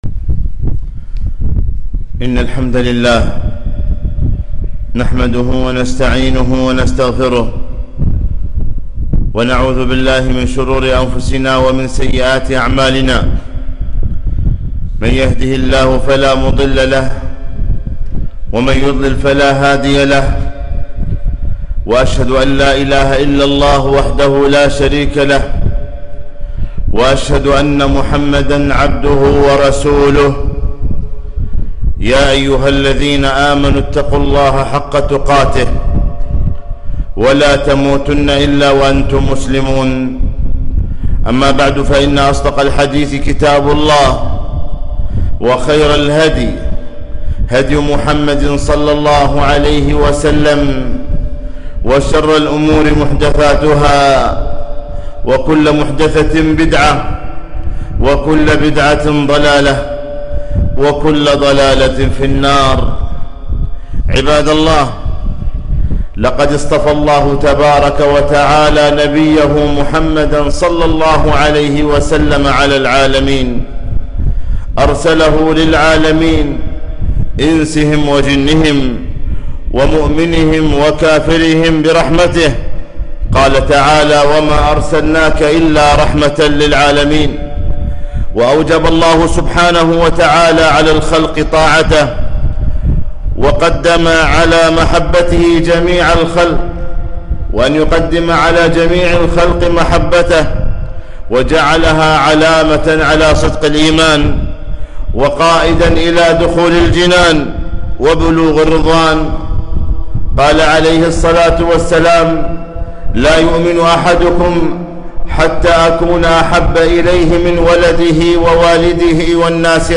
خطبة - محبة النبي صلى الله عليه وسلم بالاتباع لا بالابتداع